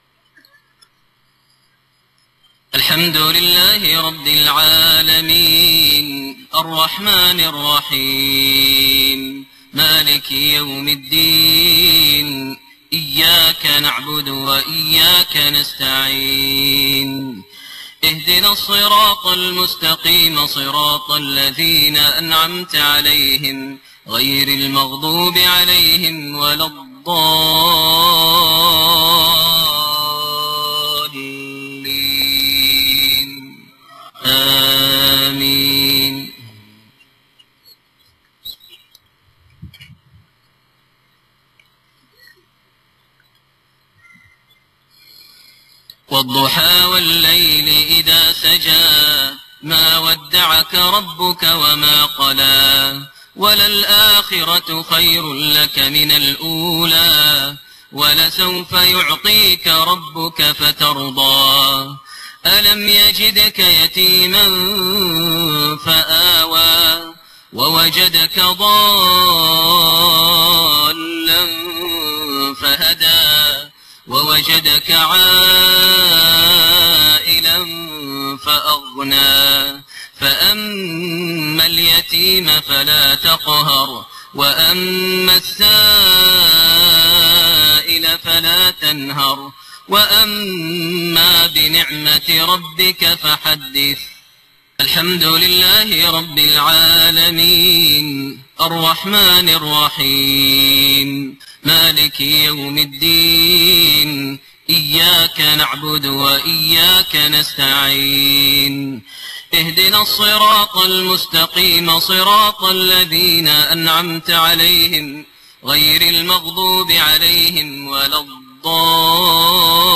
Maghrib prayer from Surat Ad-Dhuhaa and Az-Zalzala > 1429 H > Prayers - Maher Almuaiqly Recitations